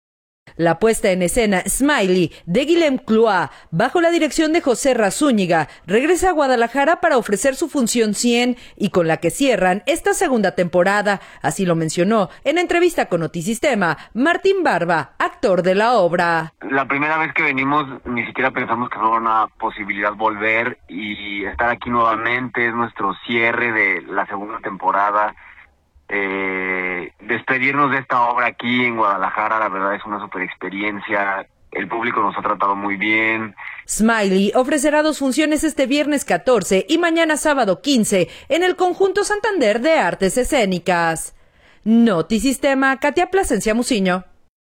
en entrevista con Notisistema